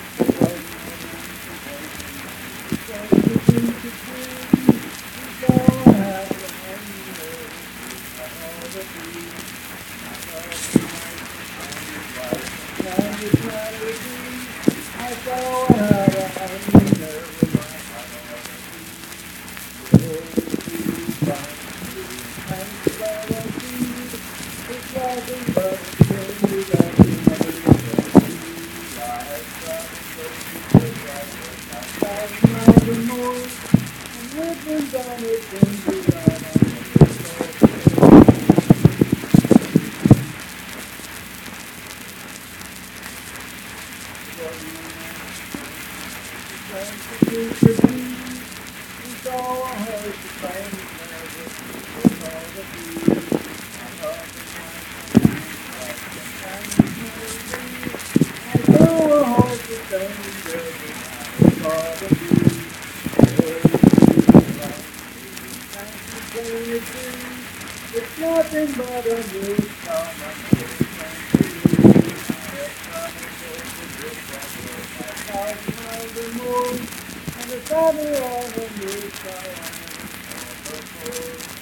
Unaccompanied vocal music performance
Verse-refrain 8(4).
Voice (sung)